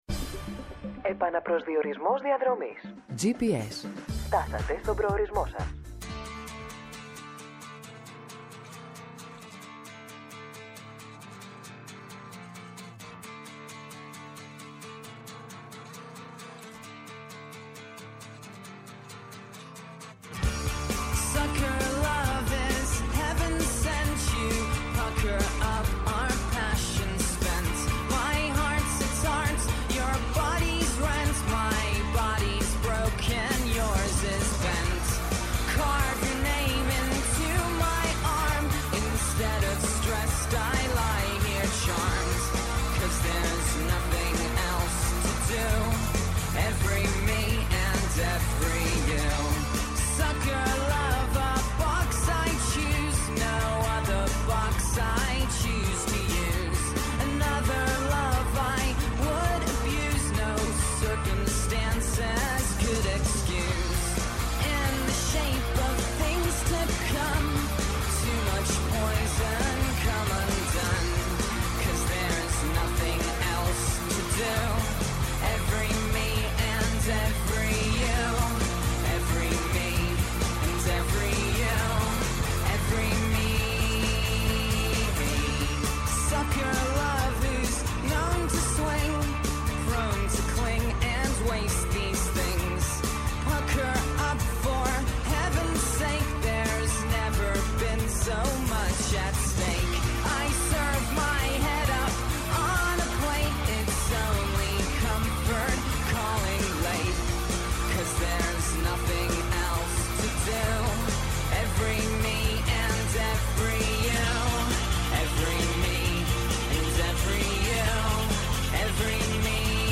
Σήμερα καλεσμένοι : -Ο Άγγελος Συρίγος, βουλευτής ΝΔ- Καθηγητής Διεθνούς Δικαίου Παντείου Πανεπιστημίου